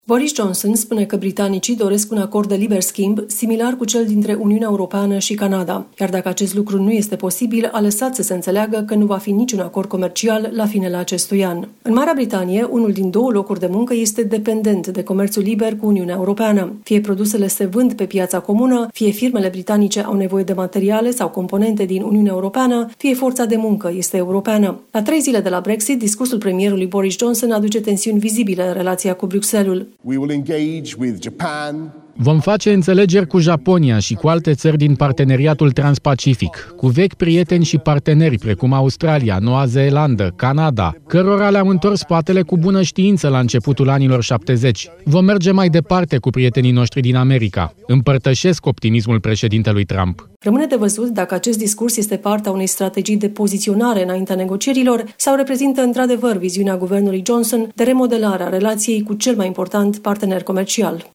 Corespondenta Europa FM